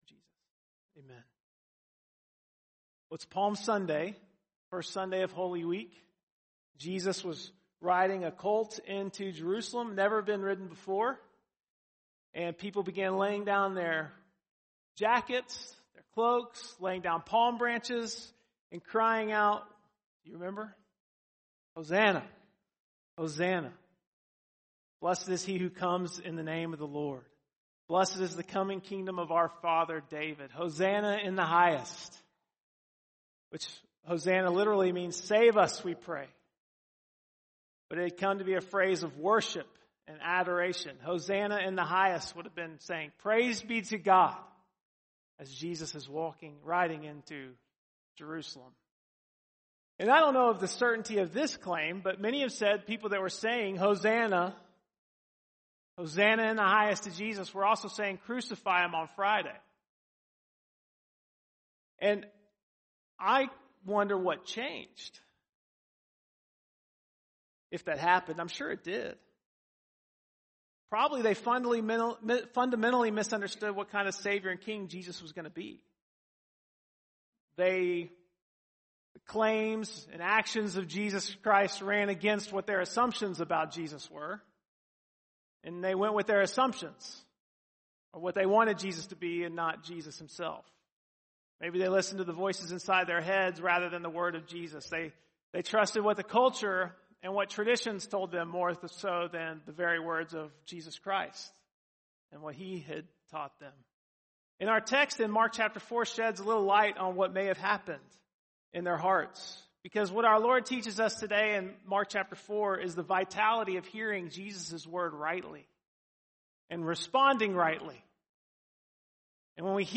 4.10-sermon.mp3